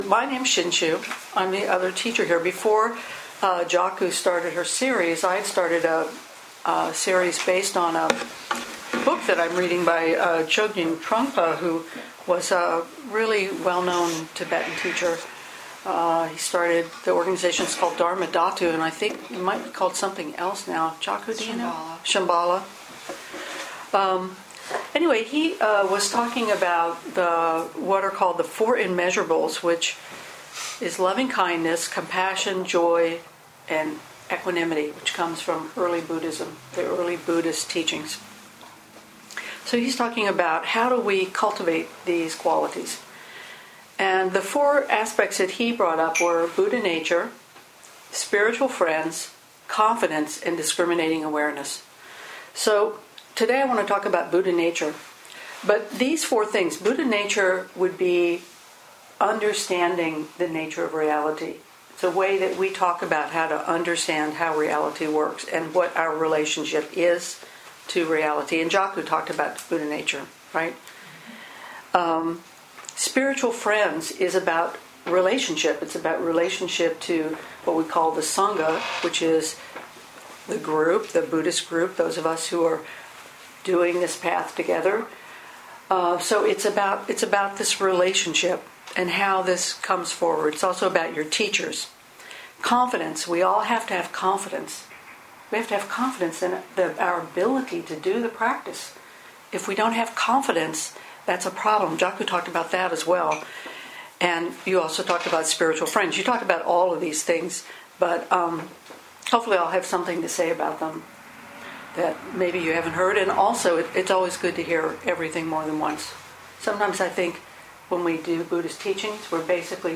2014 in Dharma Talks